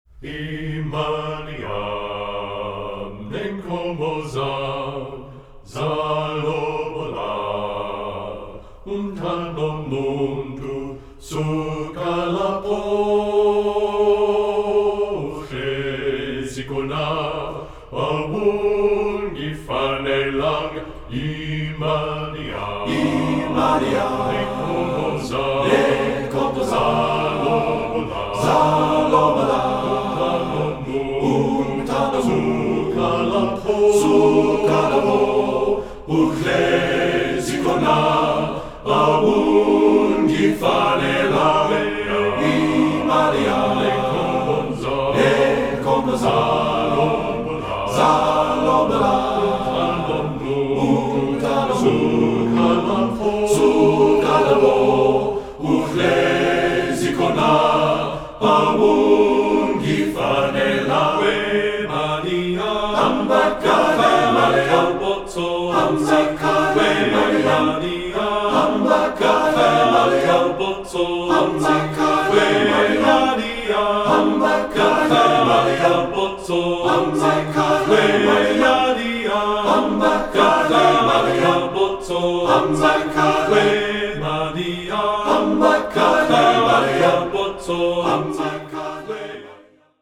Choral Male Chorus Multicultural
TTBB A Cap